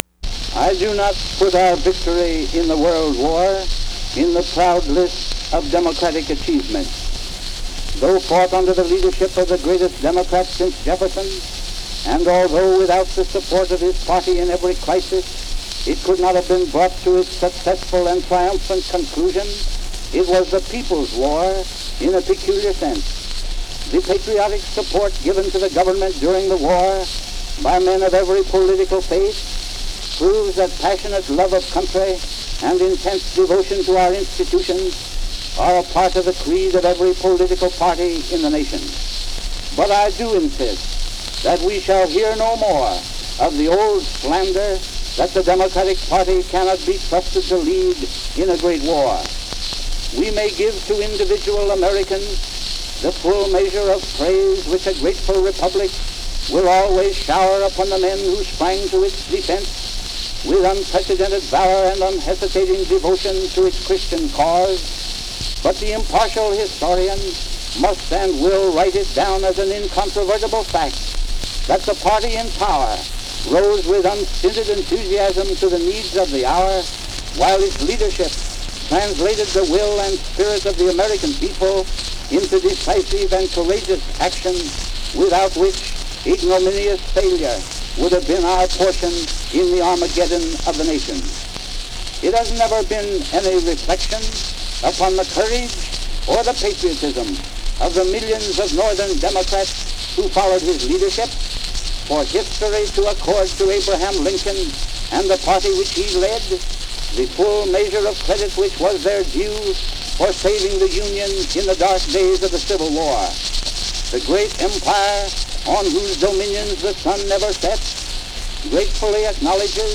Attorney General of the United States Alexander Palmer speaking as a presidential candidate at the 1920 Democratic Convention. He speaks about Americans and their courageous action during World War I, and the importance of mixing party philosophies in time of war.
World War (1914-1918) Political parties Presidents--Election United States Material Type Sound recordings Language English Extent 00:04:11 Venue Note Recorded by Nation's Forum, 1920.